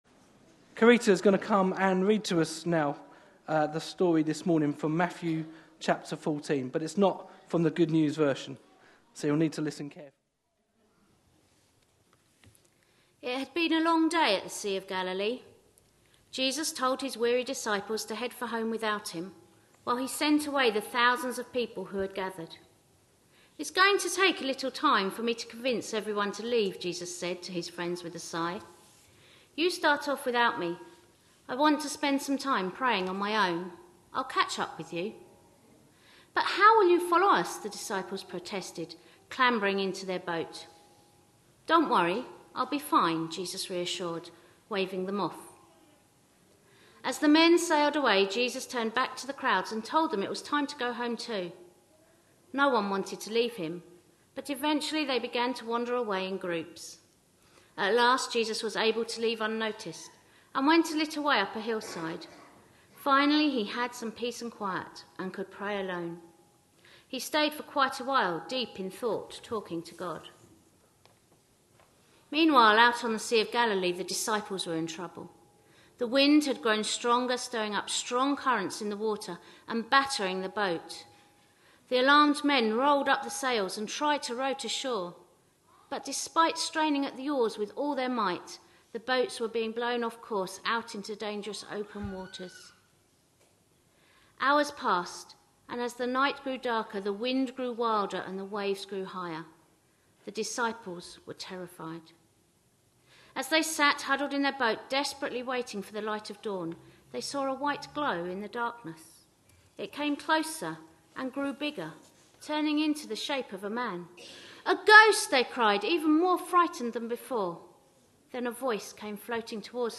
A sermon preached on 17th August, 2014, as part of our On The Beach. series.